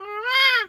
bird_peacock_squawk_soft_07.wav